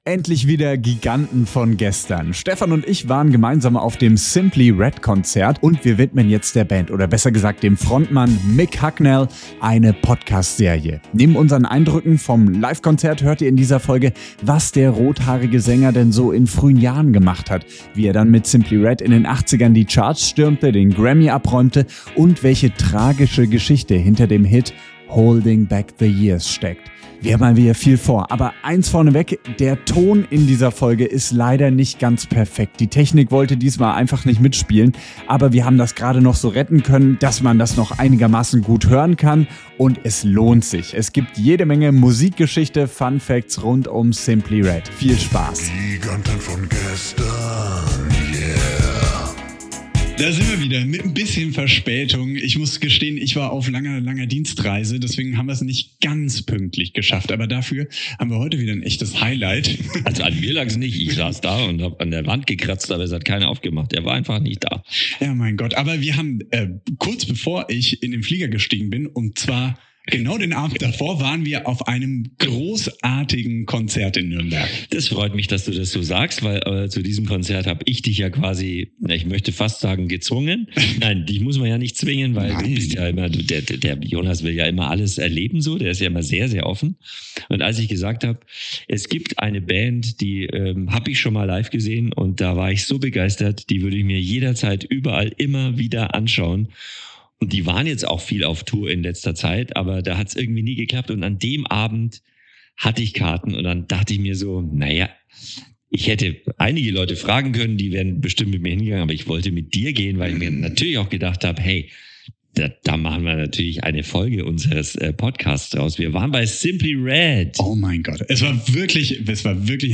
---- Hinweis: Der Ton dieser Folge ist nicht perfekt, die Technik wollte diesmal nicht so recht mitspielen. Wir konnten die Aufnahme aber so retten, dass sie gut hörbar ist.